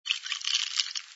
sfx_ice_moving07.wav